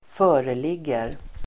Ladda ner uttalet
Uttal: [²f'ö:relig:er]